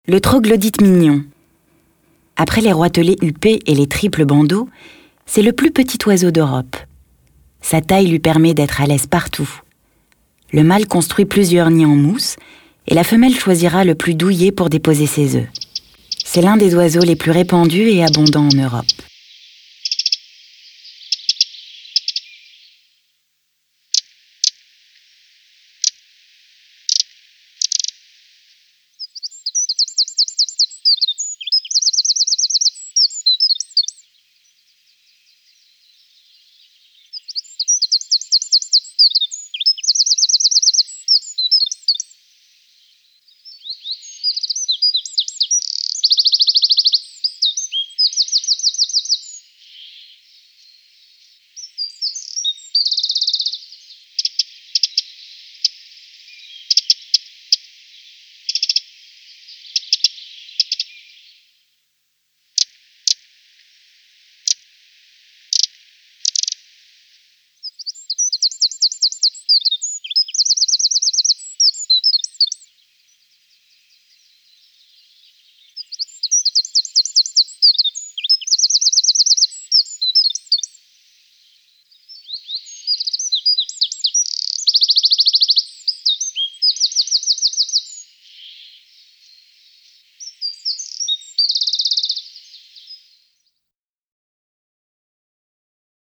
Index of / stock ancien/6/09_le_carnaval/sons oiseaux
Troglodyte.mp3